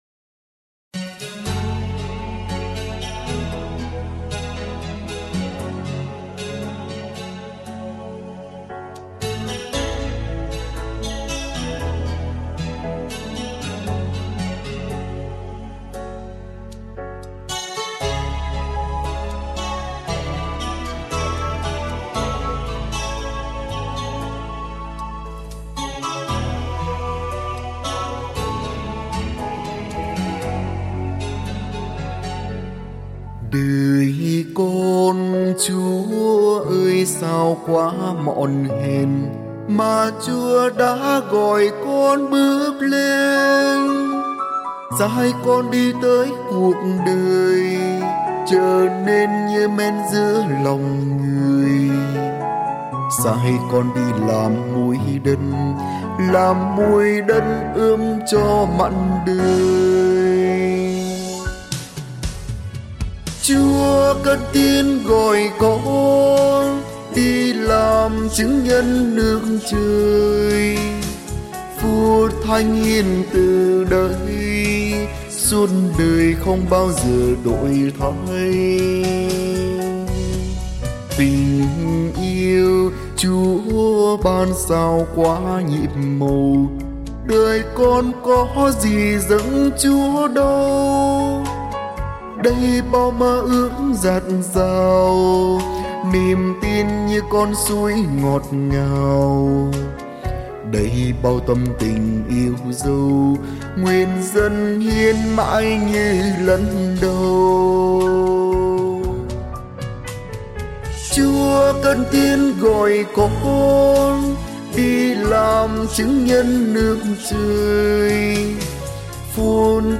Thể loại: Tận Hiến